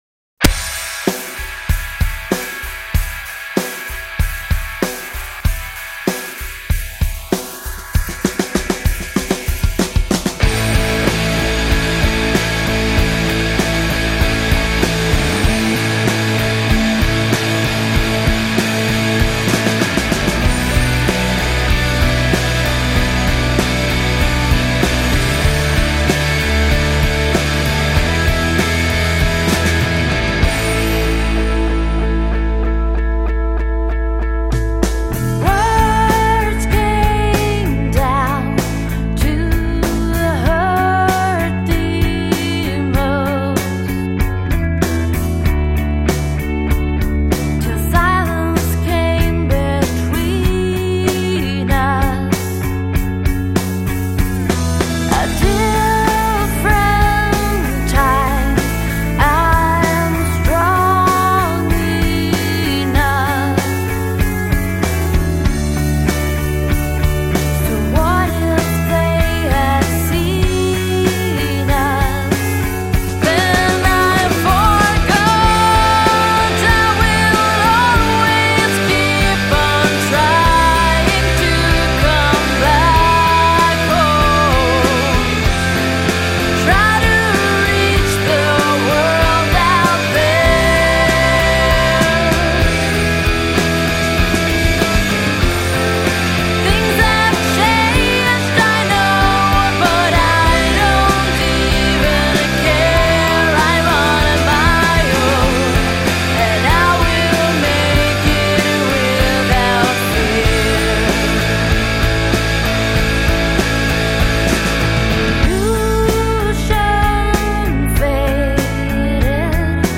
Vocals
Guitar/Vocals
Bass/Vocals
Drums